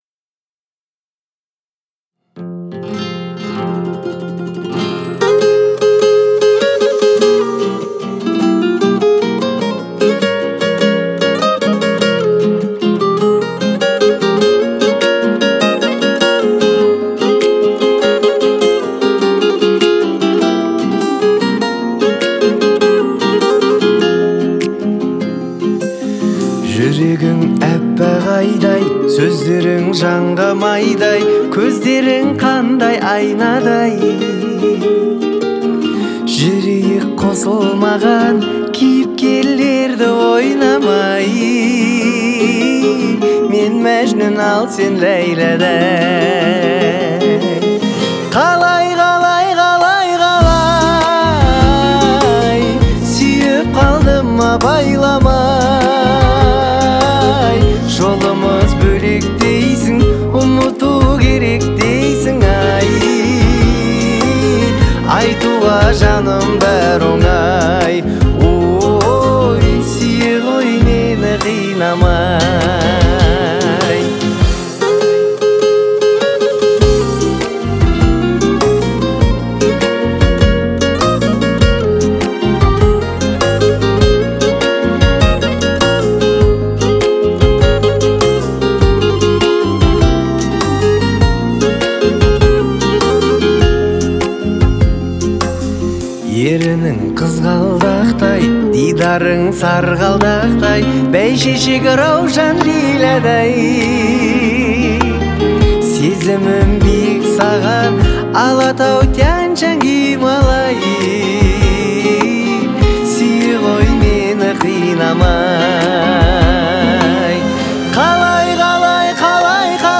Настроение трека – меланхоличное, но с нотками оптимизма.